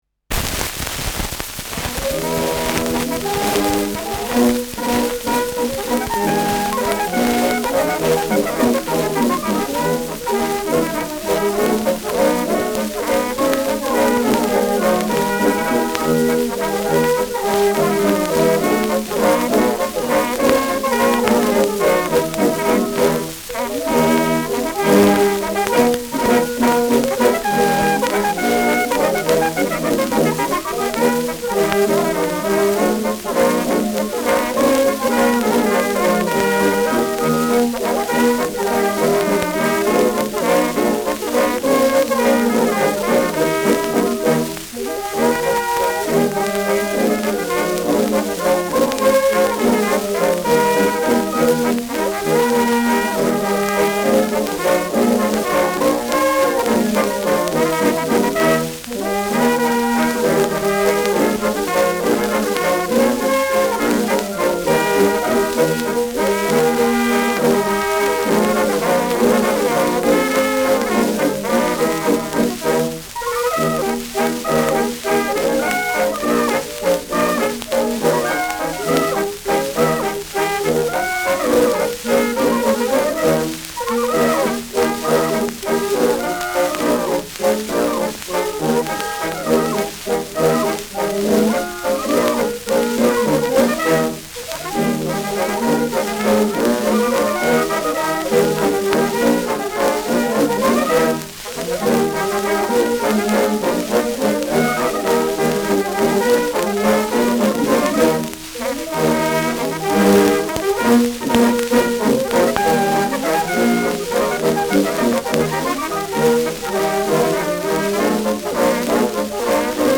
Schellackplatte
präsentes Rauschen : gelegentliches „Schnarren“ : präsentes Knistern : leiert : abgespielt